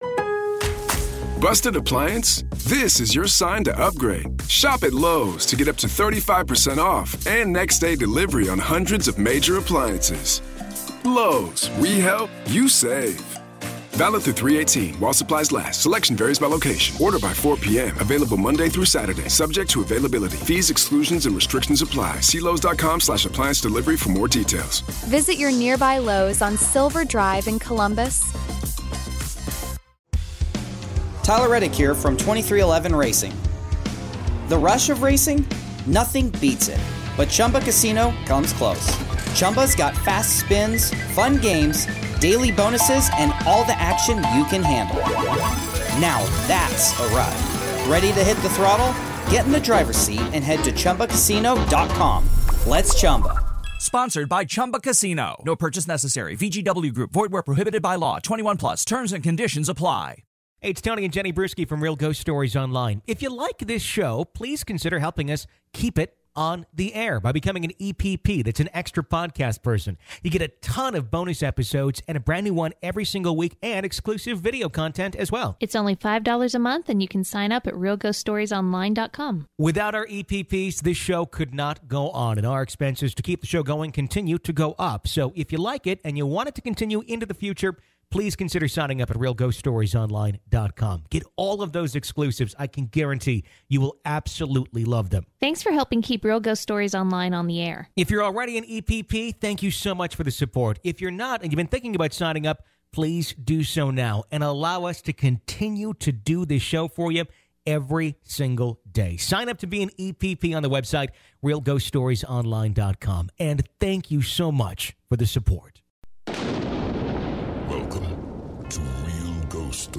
We take your real ghost story calls and hear stories of hauntings and demonic entities.